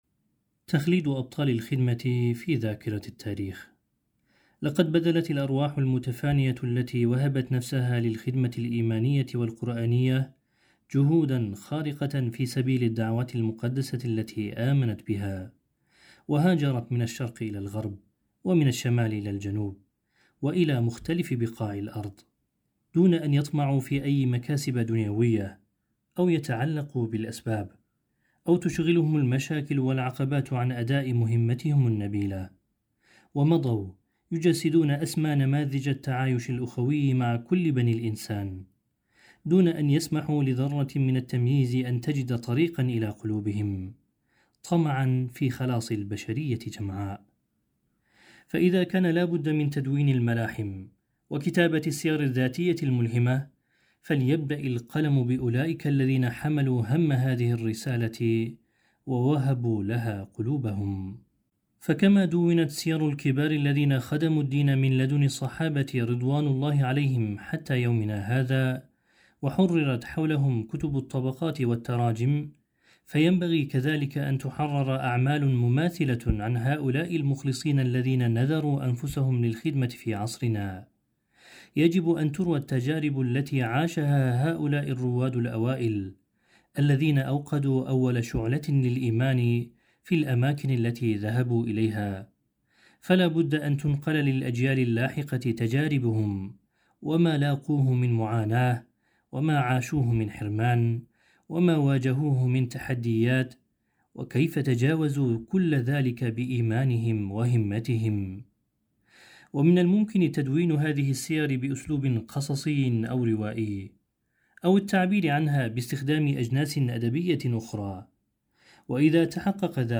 تخليد أبطال الخدمة في ذاكرة التاريخ - Fethullah Gülen Hocaefendi'nin Sohbetleri